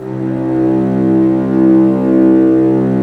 Index of /90_sSampleCDs/Roland L-CD702/VOL-1/STR_Vcs Bow FX/STR_Vcs Sordino